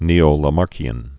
(nēō-lə-märkĭzəm)